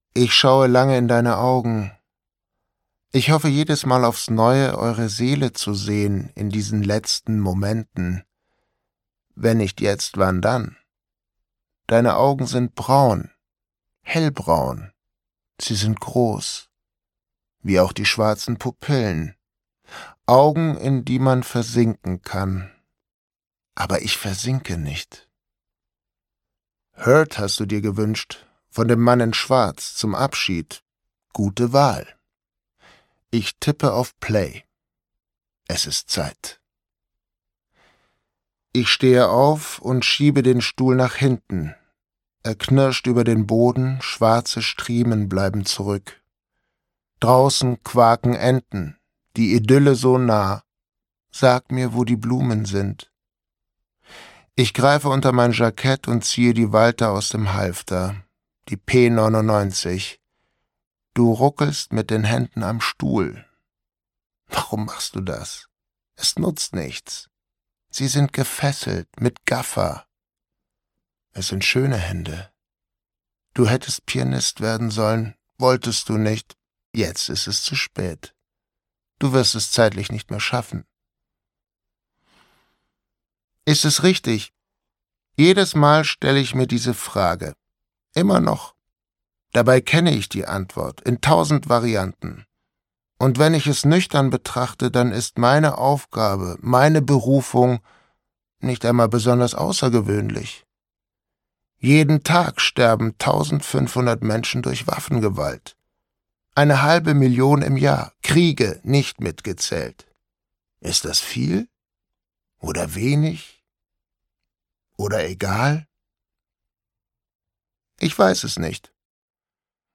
Billy - einzlkind - Hörbuch